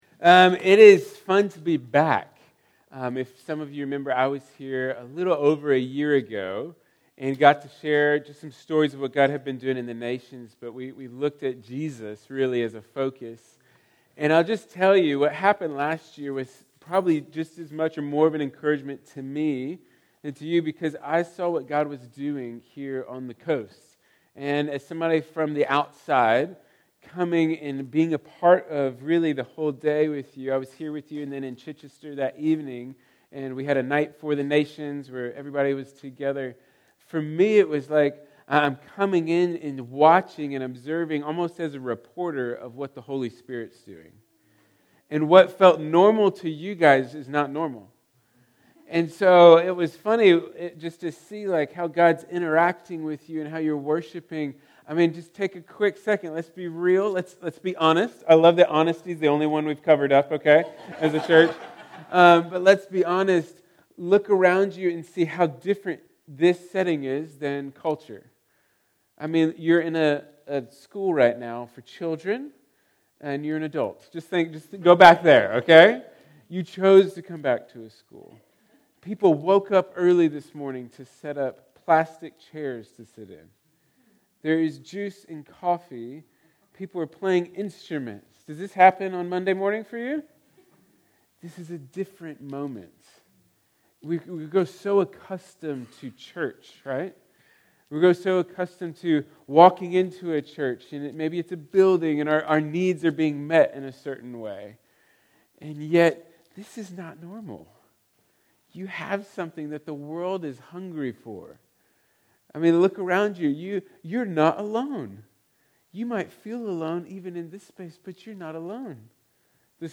Series: Other Sermons 2025